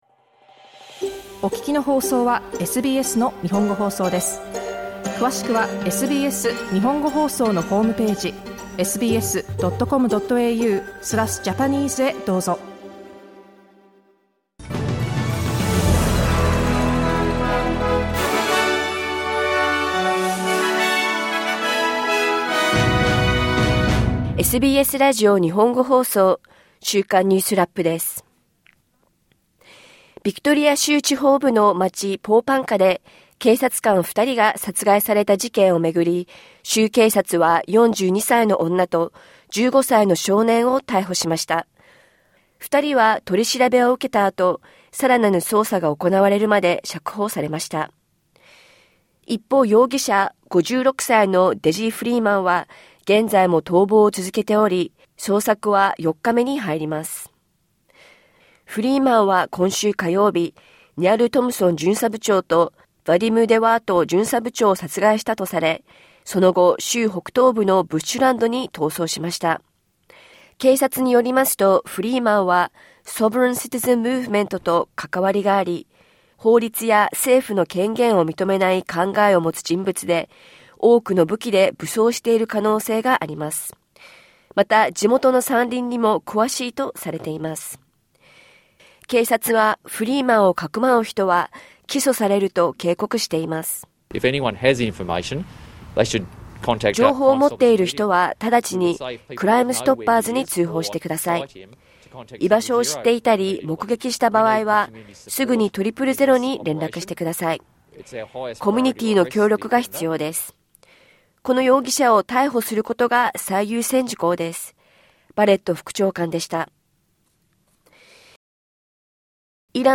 SBS日本語放送週間ニュースラップ 8月29日金曜日
1週間を振り返るニュースラップです。